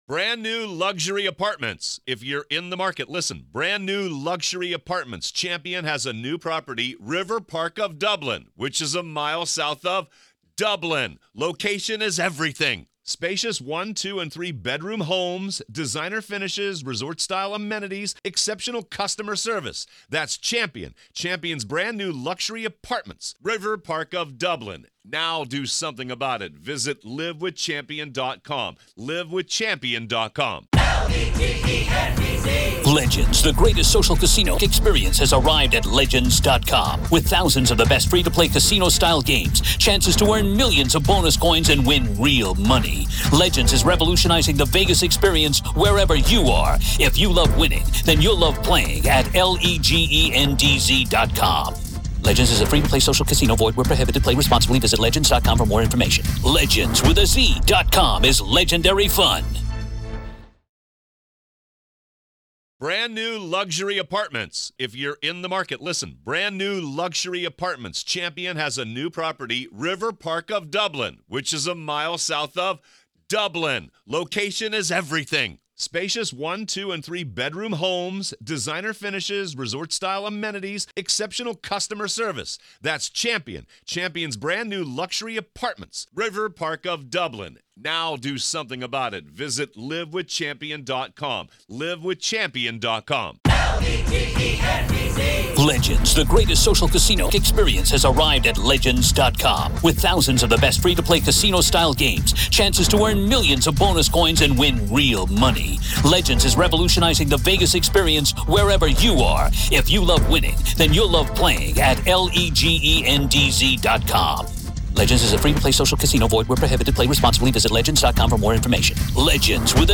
This is audio from the courtroom in the high-profile murder retrial